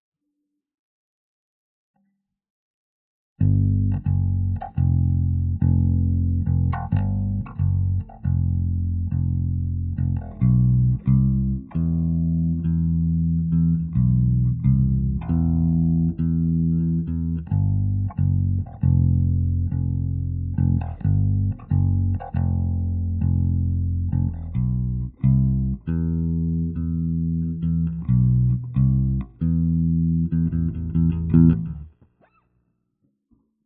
Bass_026 (2).wav